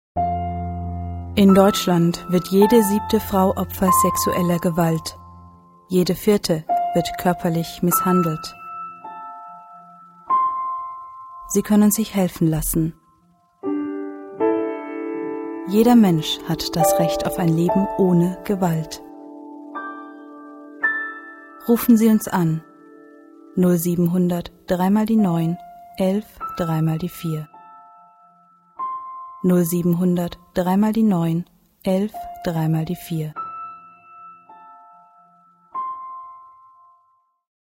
Radiospot
radiospot.mp3